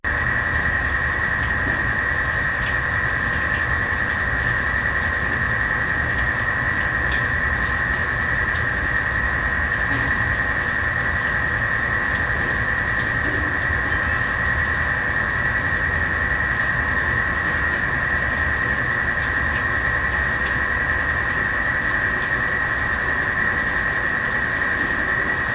机器嗡嗡声3
描述：另一个Xbox 360的机械/电气嗡嗡声，这次是用ZOOM（不记得是哪个型号了，因为我是为了一个学校的项目而租的）靠近机器后面的地方录制的，我相信
标签： 嗡嗡声 360 氛围 环境 噪音 机械 XBOX 机械 哼哼 工业 声音
声道立体声